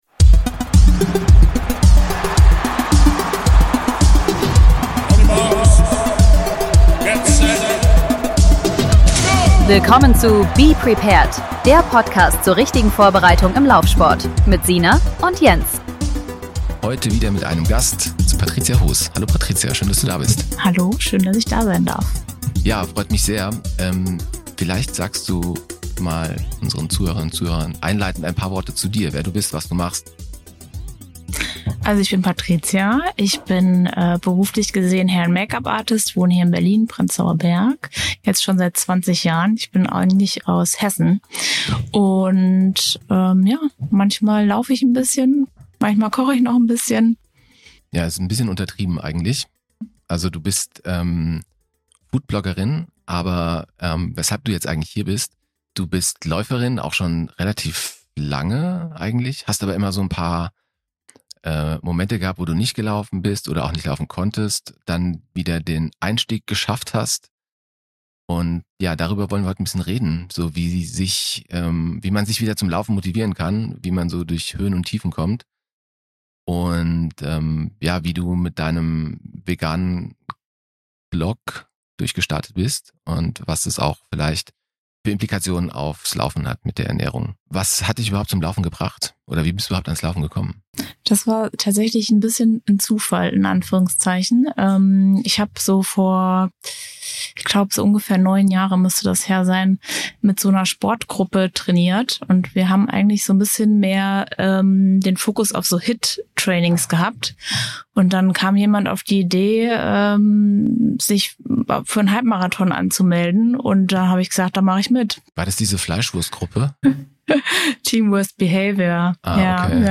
Ein Gespräch über den inneren Schweinehund und wi...